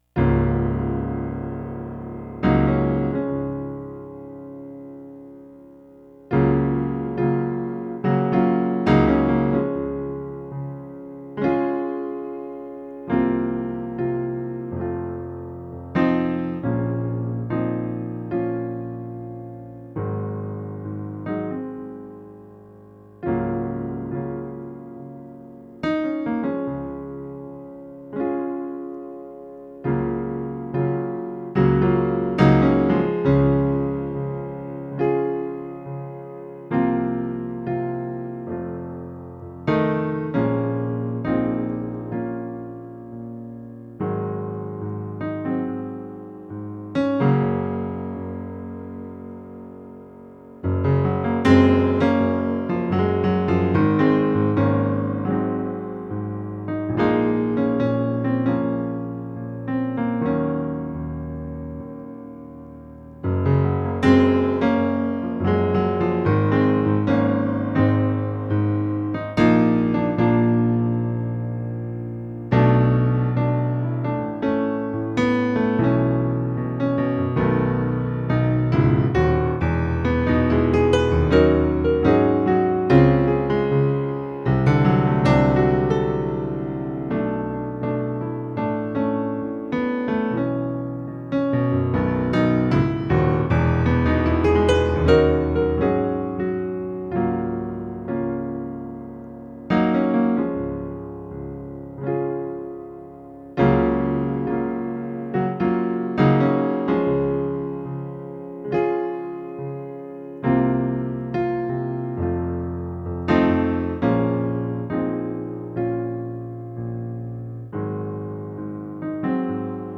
on a digital piano plugged into the audio in of a computer.